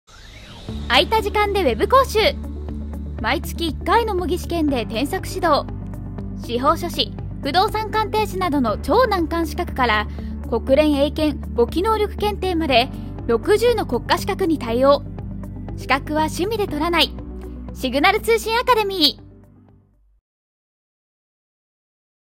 丁寧・高品質・リーズナブルなプロの女性ナレーターによるナレーション収録
商材PR